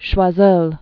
(shwä-zœl)